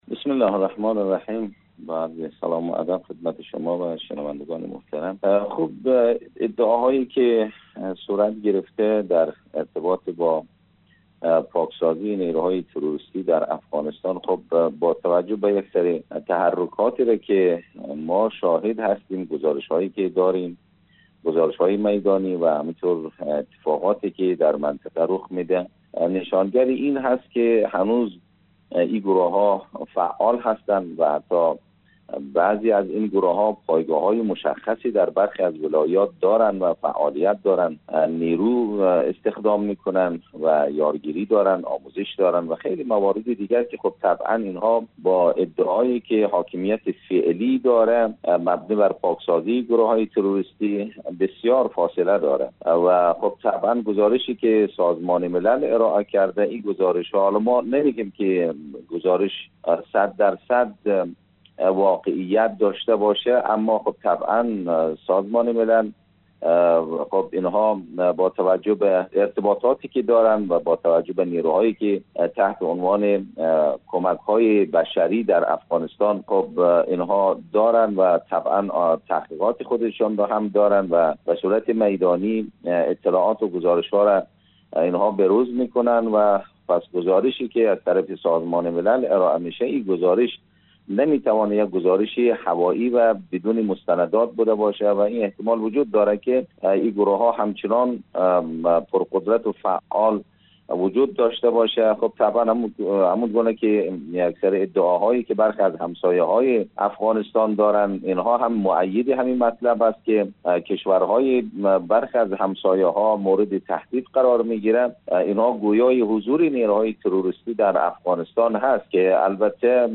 خبر / مصاحبه